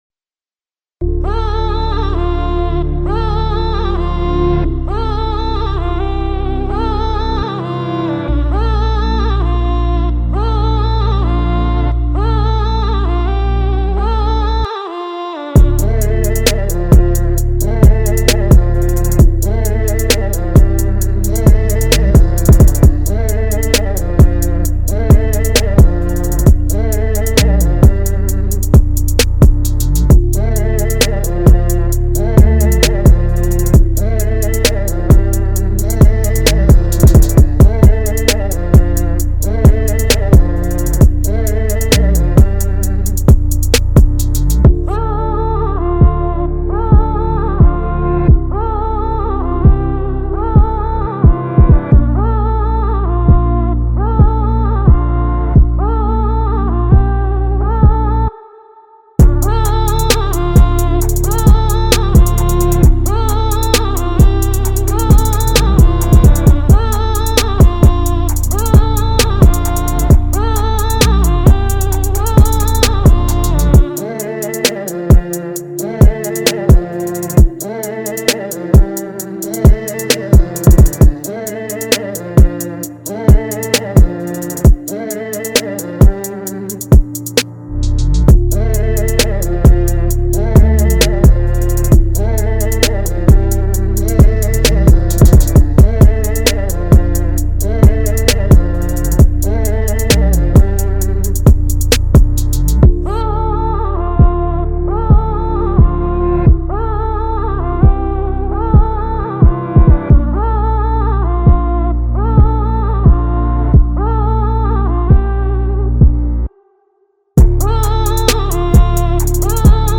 Here's the official instrumental
Rap Instrumental